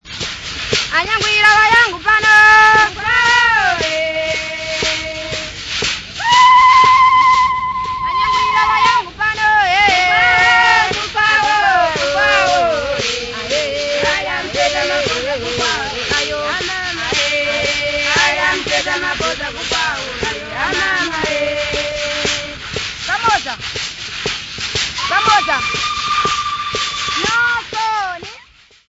Group of 4 Tonga women
Folk music--Africa
Folk songs, Tonga (Nyasa)
field recordings
Anyangwila went off to her mother and lied to her. Sekese dance song with 4 raft rattles.
96000Hz 24Bit Stereo